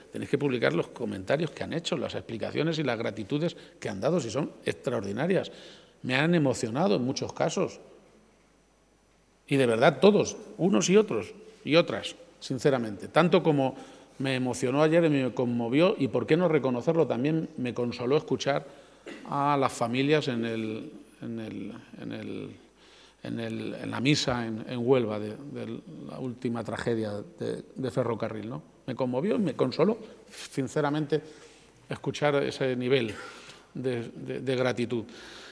garcia-page_emocion_palabras_victima_accidente_tren.mp3